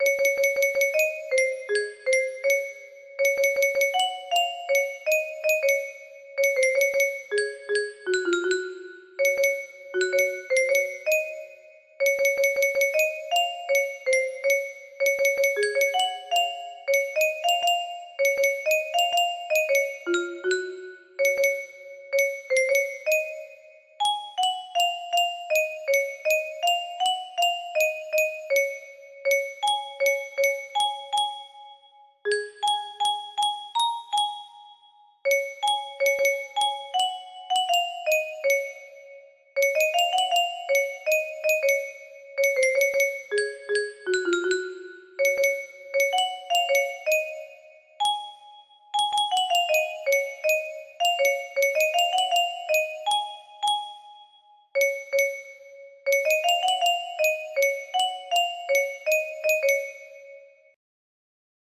code: C# major